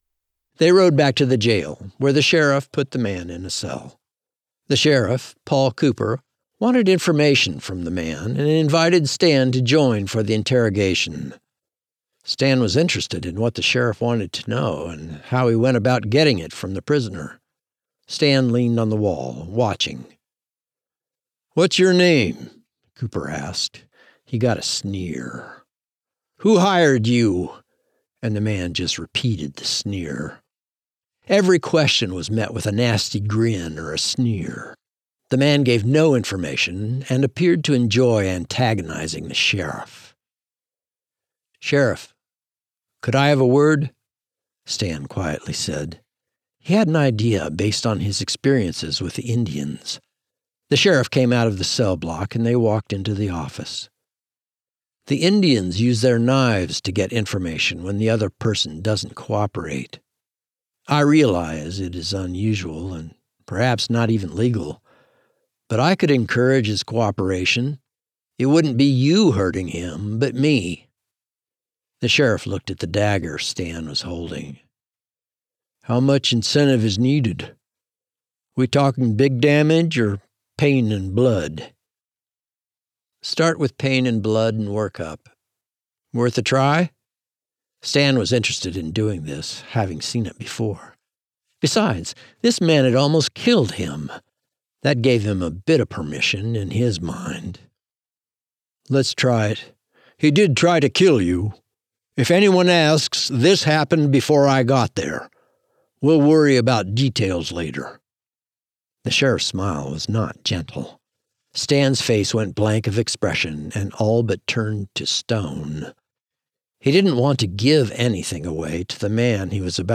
Hopefield Evolution audiobook